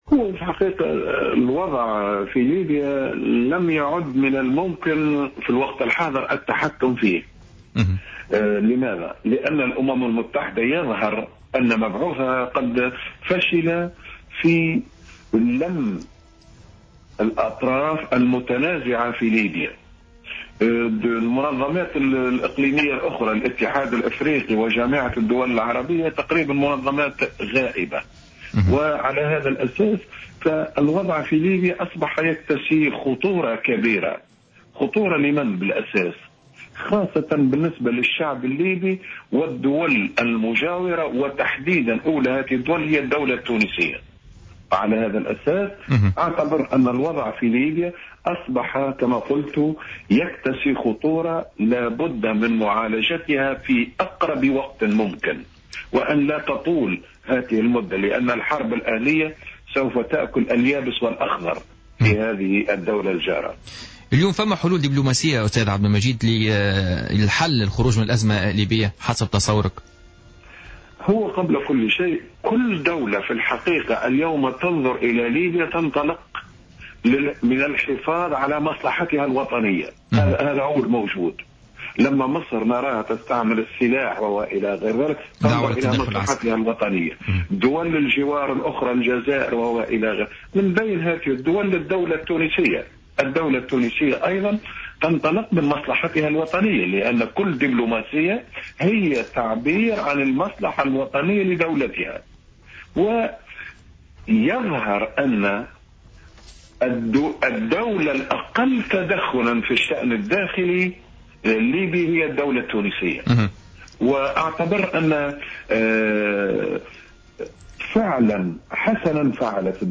في مداخلة له اليوم في برنامج "بوليتيكا"